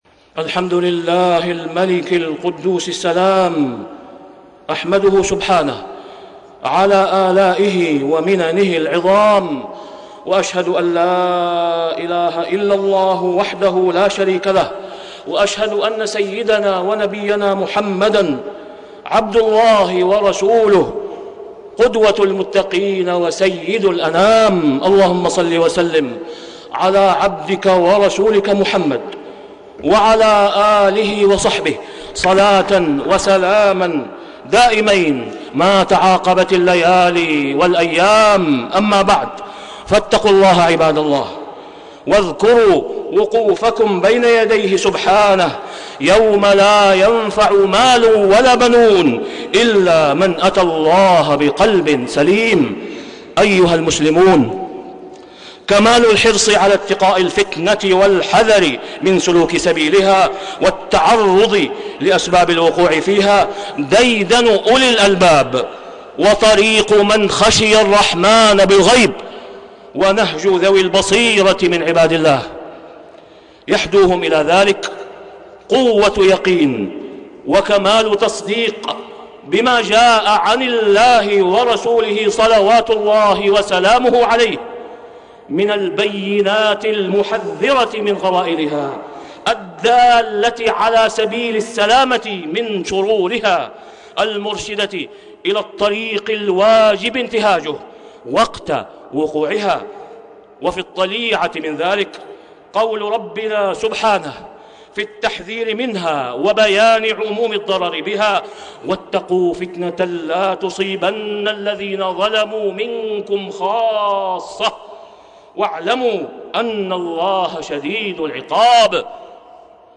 تاريخ النشر ١٥ صفر ١٤٣٤ هـ المكان: المسجد الحرام الشيخ: فضيلة الشيخ د. أسامة بن عبدالله خياط فضيلة الشيخ د. أسامة بن عبدالله خياط إتقاء الفتن